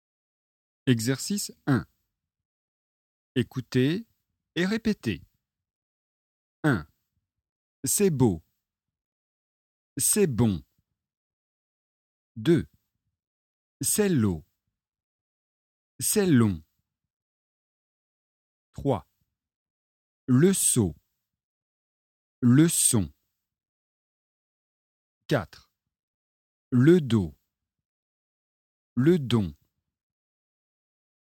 • Leçon de phonétique et exercices de prononciation